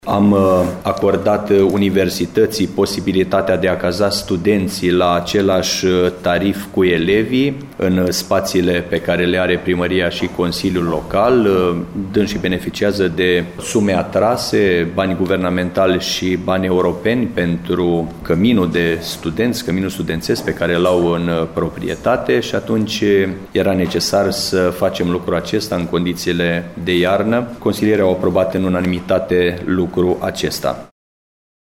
Ei vor plăti aceleaşi taxe de cazare ca şi elevii, respectiv 60 de lei pe lună, spune primarul municipiului Reşiţa, Mihai Stepanescu: